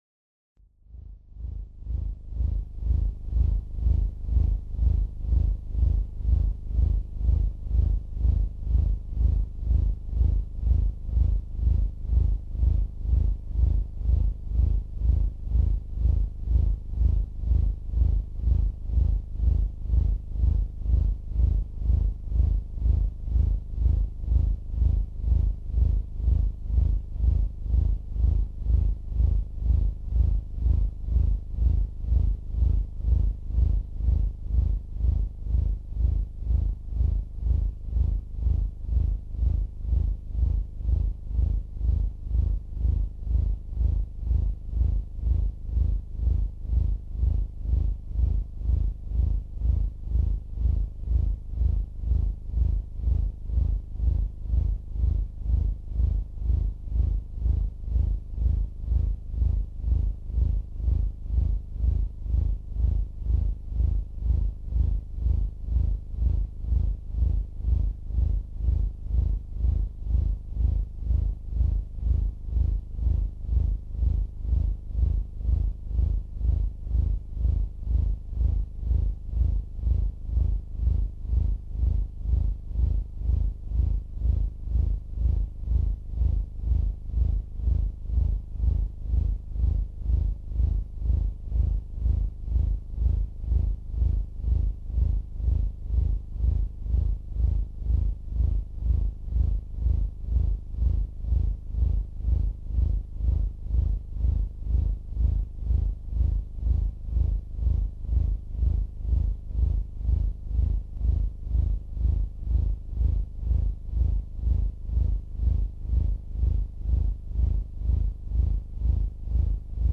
An Intelligent Machinery hosted Monthly Internet Collaboration Noise Free-For-All concert event. This event is open to the public for all interested noise, dark ambient, electroacoustic and abstract sound dabblers who want to participate in a live improvised collaboration of sound.
To make giant walls of noise with the aid of other anti-music miscreants from the comfort of your own house.